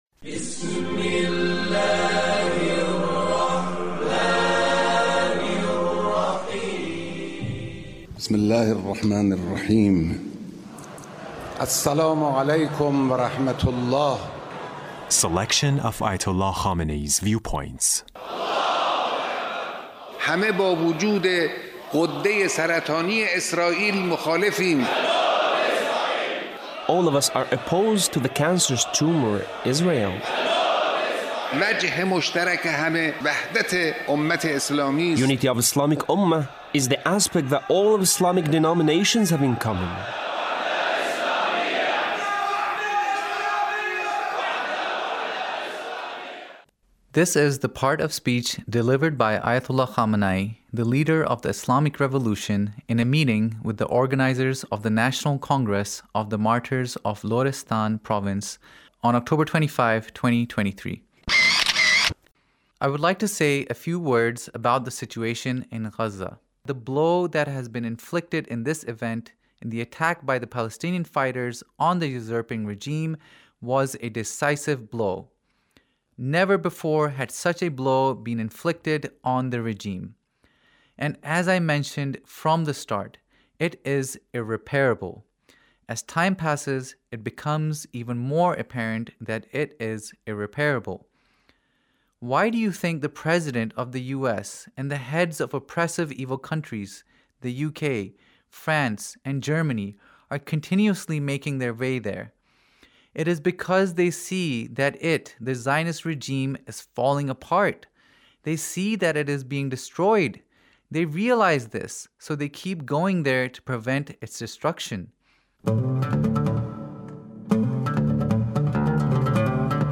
Leader's Speech (1866)
Leader's Speech about Palestine